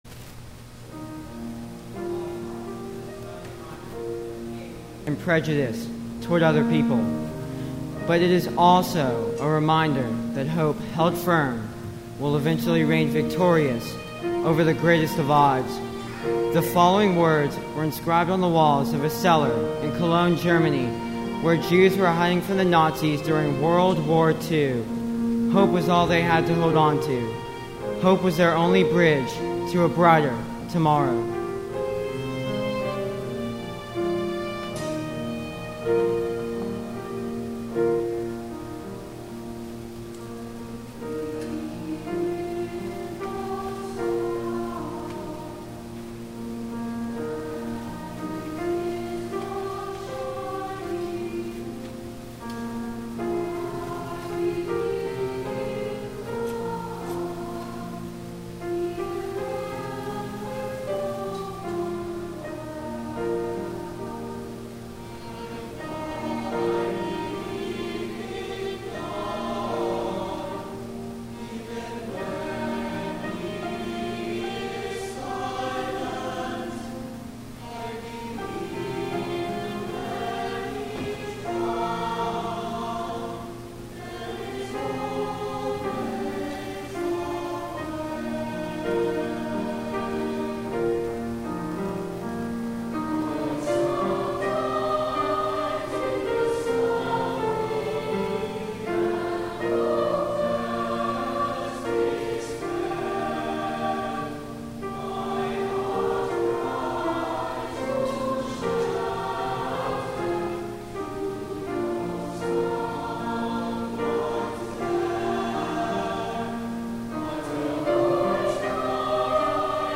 THE OFFERTORY
Inscription of Hope  Russian folk tune; arr. Z. Randall Stroope